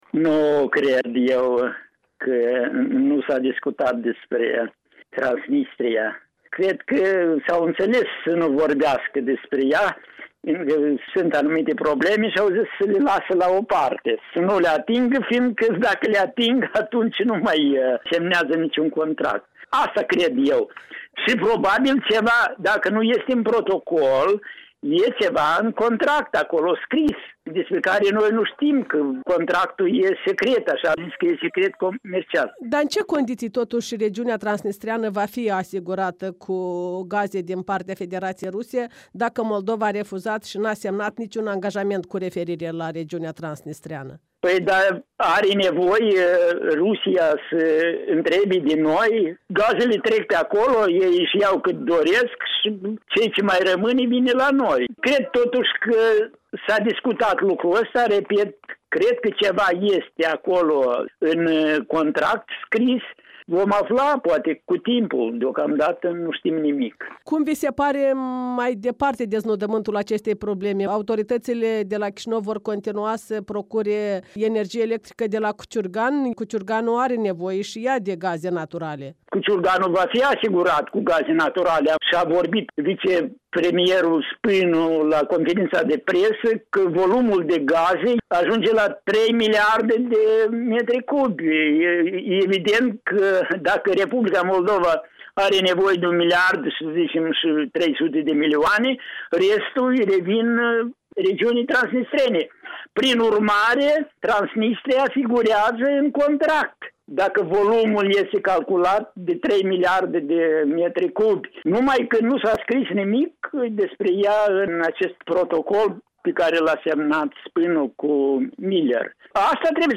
în dialog cu comentatorul politic